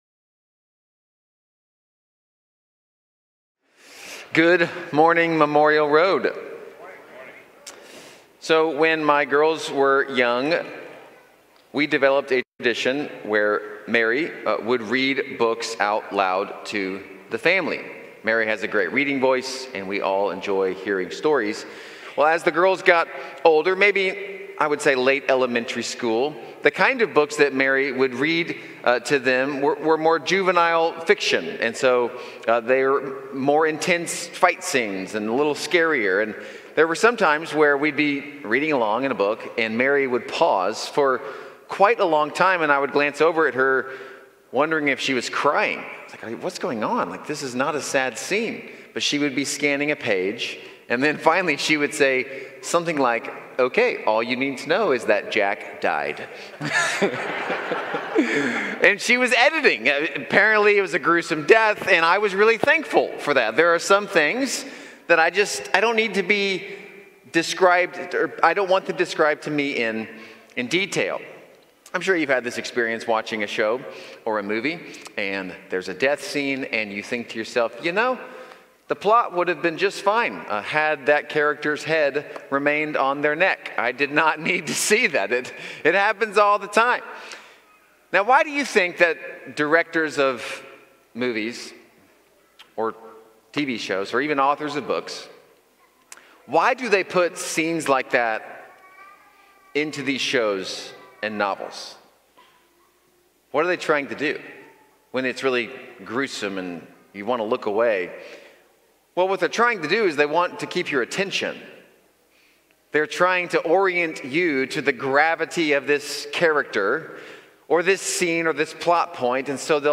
Series: Believe in Me, Sunday Morning